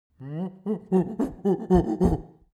Excited Monkey Grunt Téléchargement d'Effet Sonore
Excited Monkey Grunt Bouton sonore